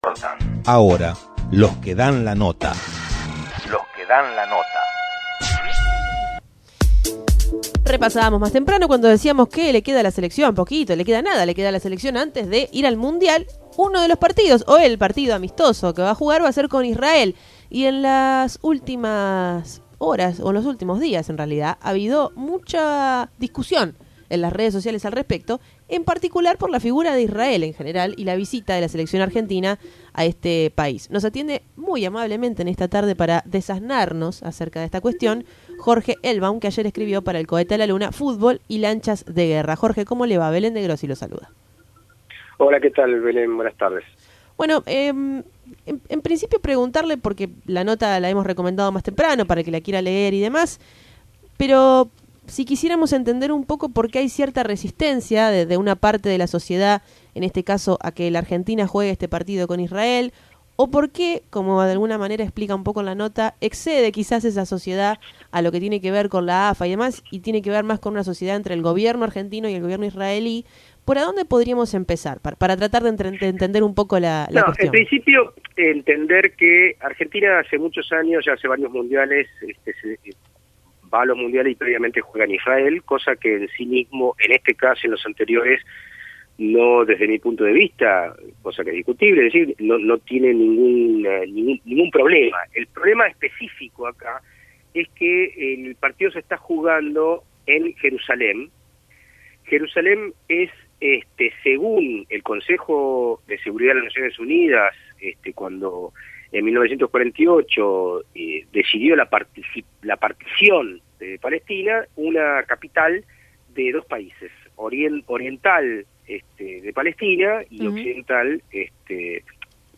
Y Tren Urbano dialogó con él: